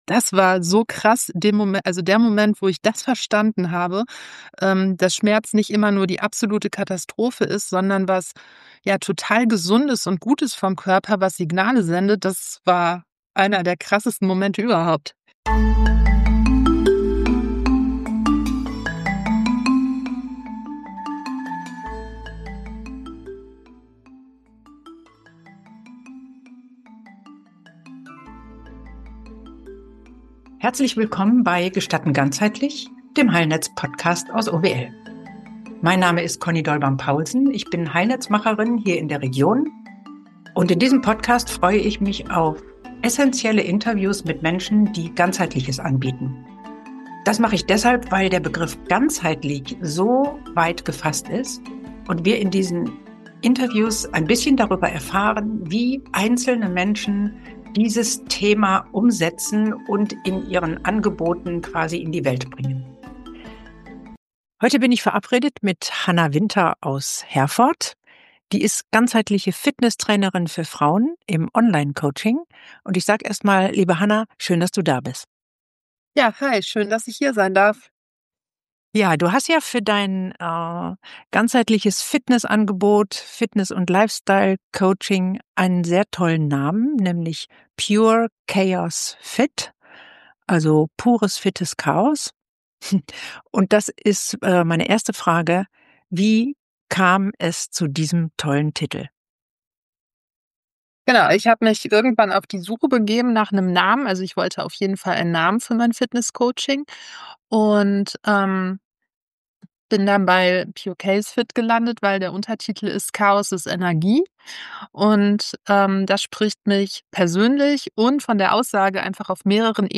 Hier hörst Du Interviews von Menschen, die im Heilnetz ganzheitliche Angebote machen und die im Podcast darüber sprechen, was ihnen dabei besonders wichtig...